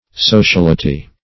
Sociality \So`ci*al"i*ty\, n. [Cf. F. socialist['e], L.